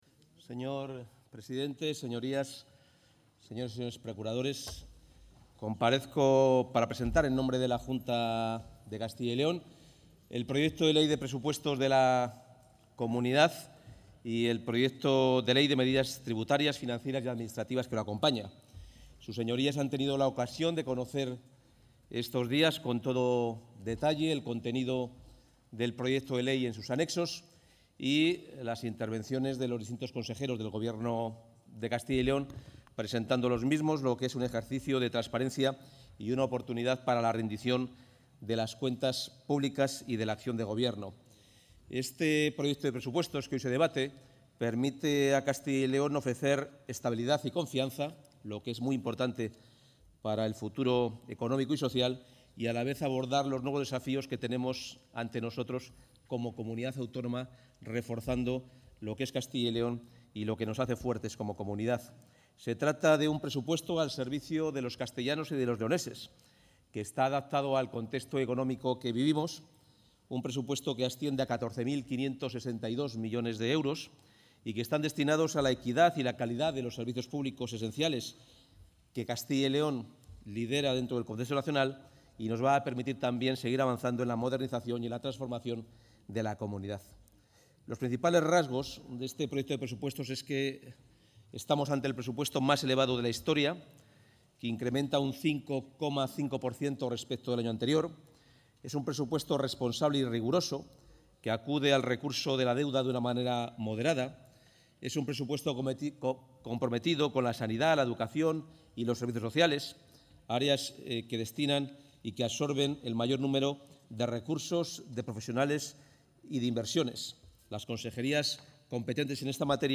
Intervención del consejero de Economía y Hacienda en la presentación del Proyecto de Ley de los Presupuestos Generales de la Comunidad para 2024 para su debate a la totalidad | Comunicación | Junta de Castilla y León
El consejero de Economía y Hacienda, Carlos Fernández Carriedo, presenta en las Cortes de Castilla y León el Proyecto de Ley de los Presupuestos Generales de la Comunidad para 2024 para su debate a la totalidad.